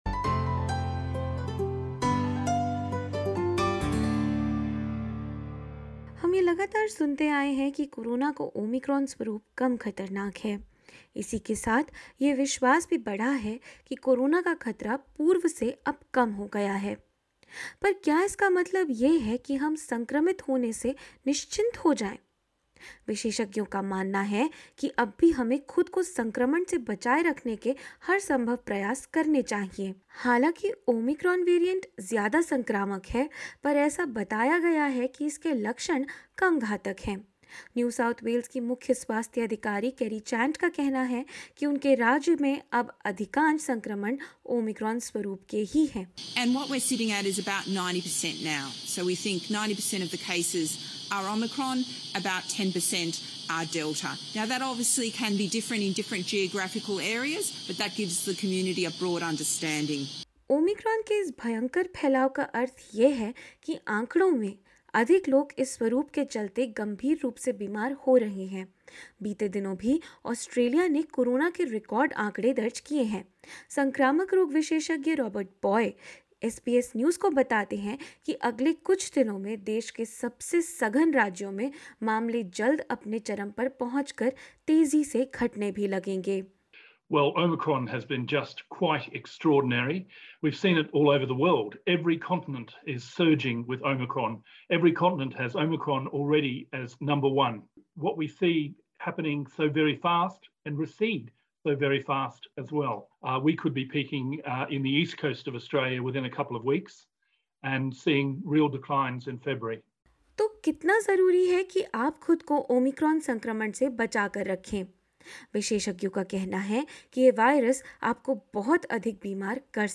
विशेषज्ञों का कहना है कि यह लापरवाही बरतने का समय नहीं है। क्या है पूरा मामला, जानेंगे इस रिपोर्ट में।